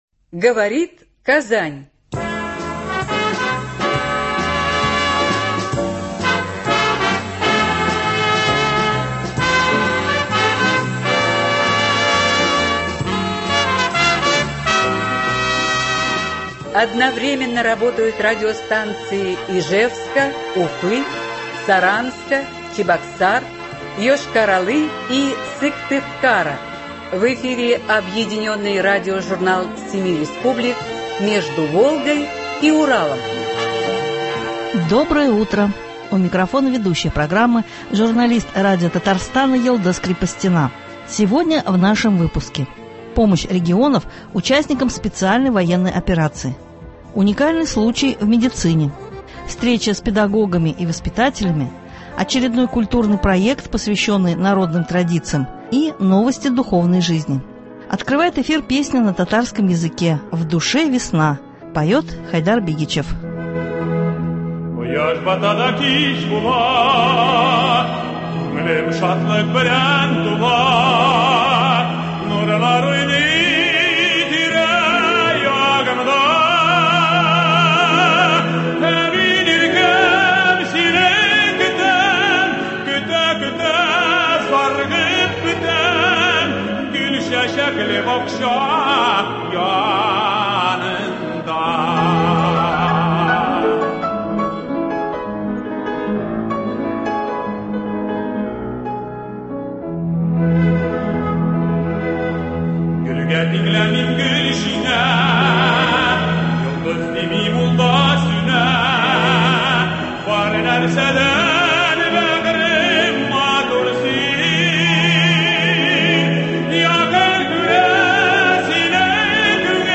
Объединенный радиожурнал семи республик.